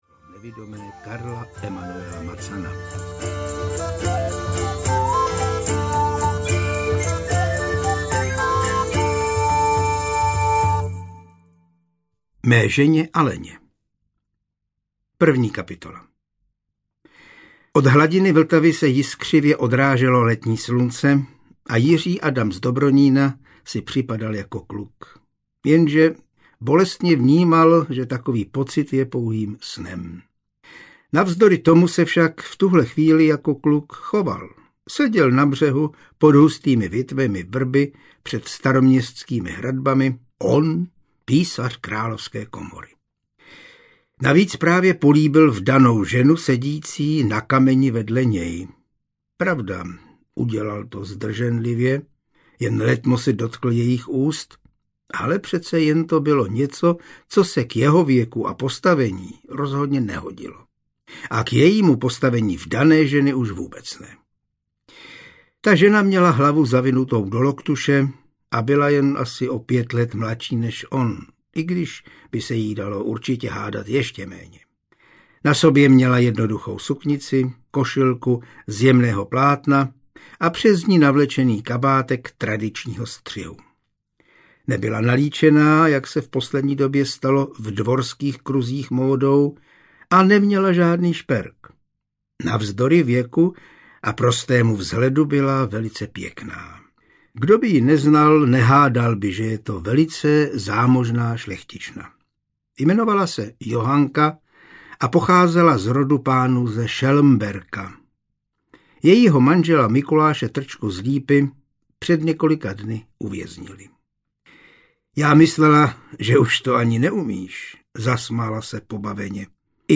Jičínské pole mrtvých audiokniha
Ukázka z knihy
jicinske-pole-mrtvych-audiokniha